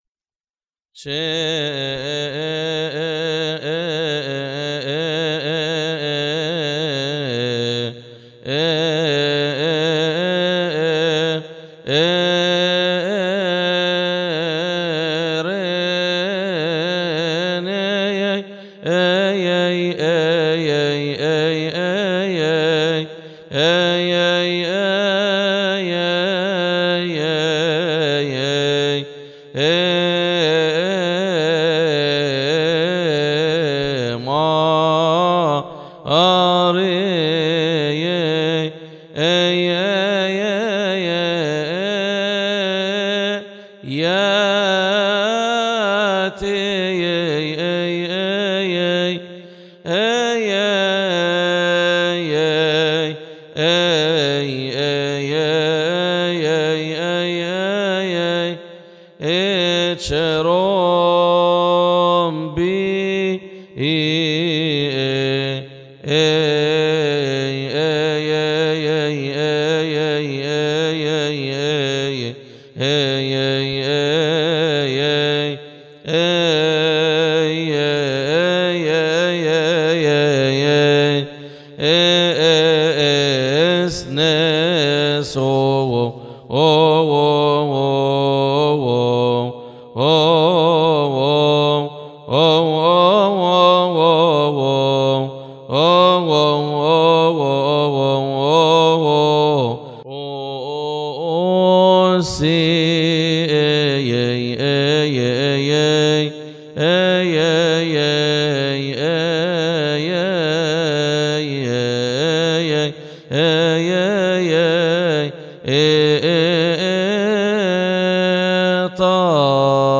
استماع وتحميل لحن لحن شيري نيه ماريا من مناسبة keahk